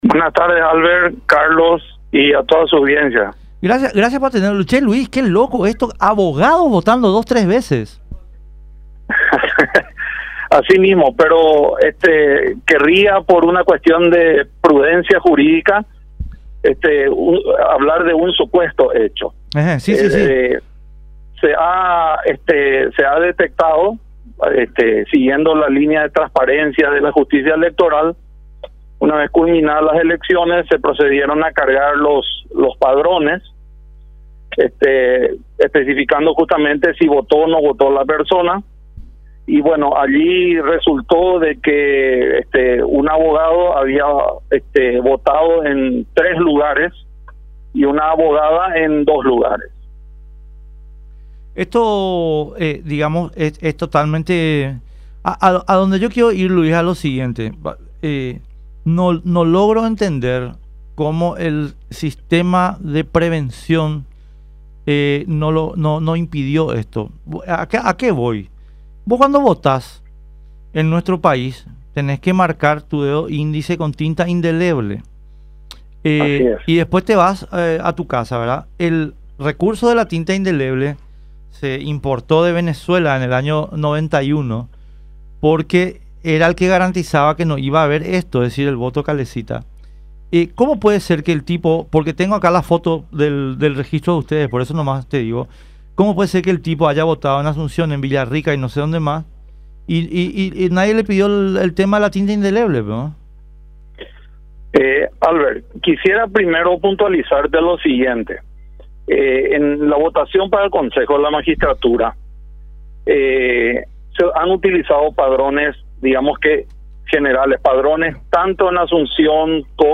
habló en contacto con La Unión R800 AM